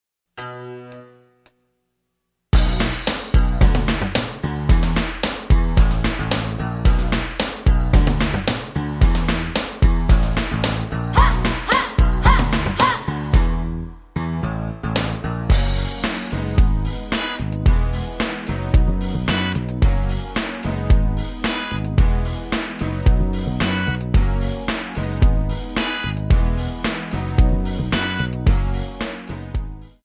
Backing track files: Pop (6706)
Buy With Backing Vocals.